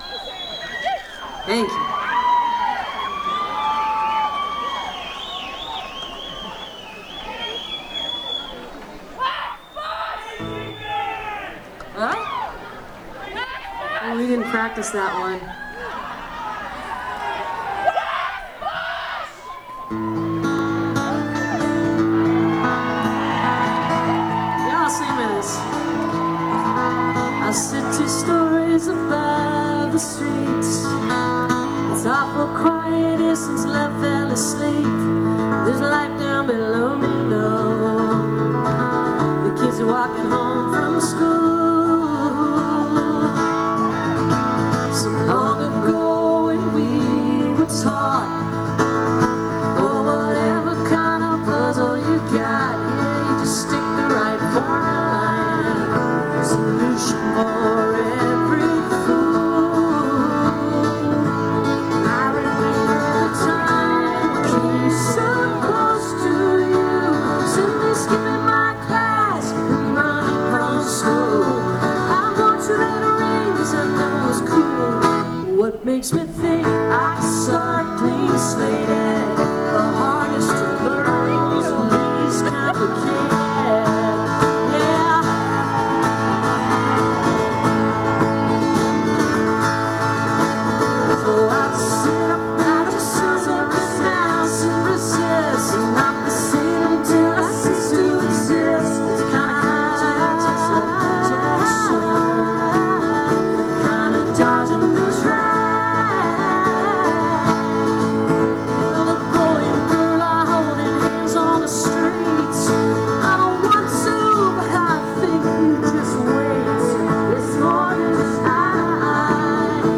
(acoustic show)